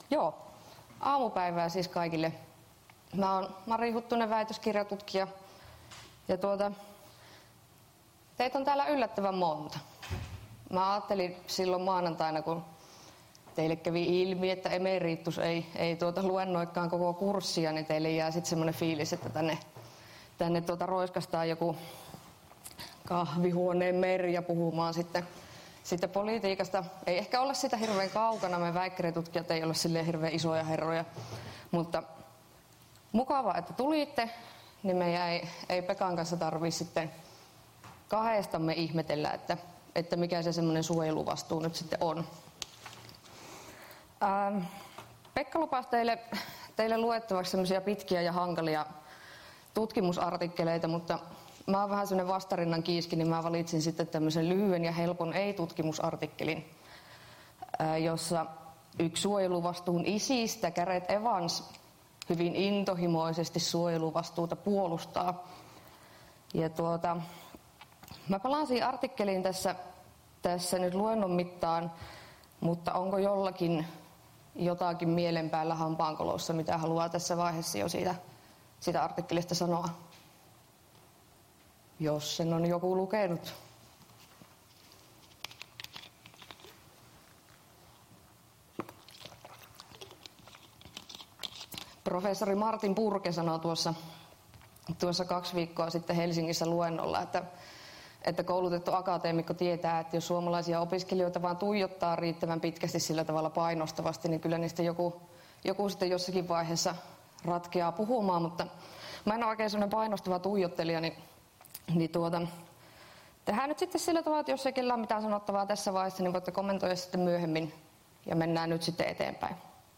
POLS3017 Luento 2 — Moniviestin